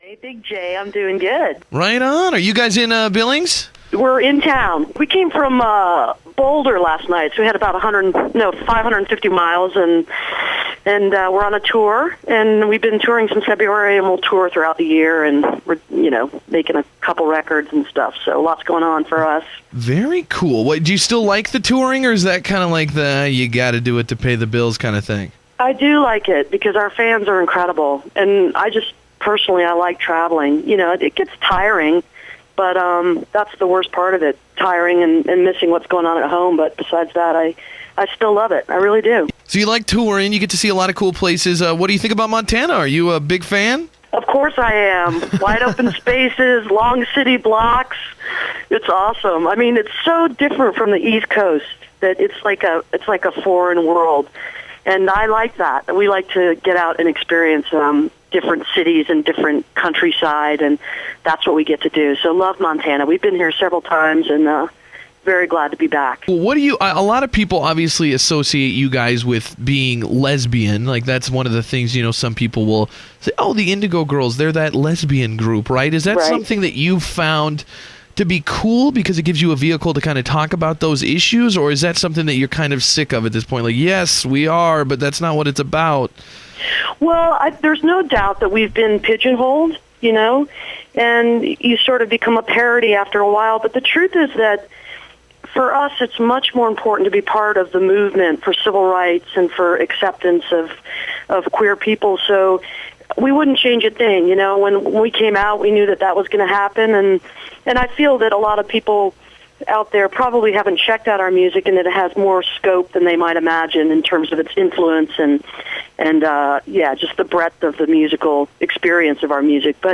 audio captured from youtube
01. interview